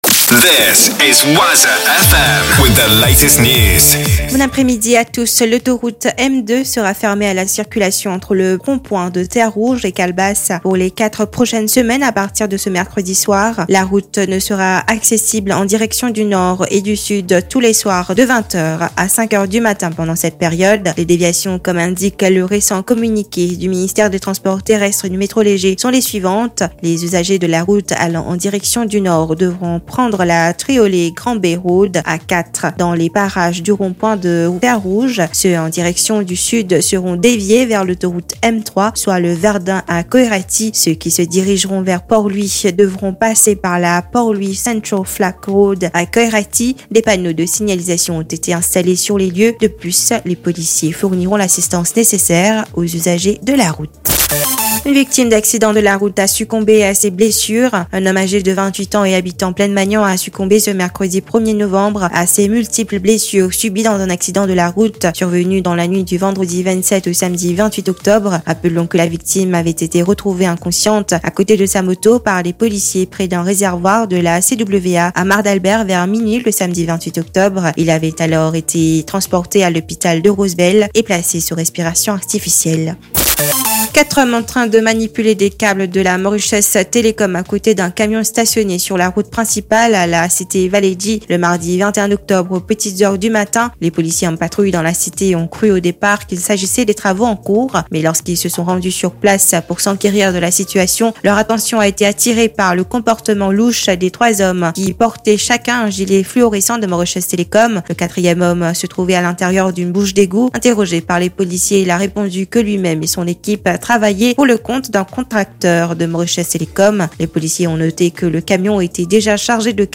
NEWS 15 H - 1 .11 .23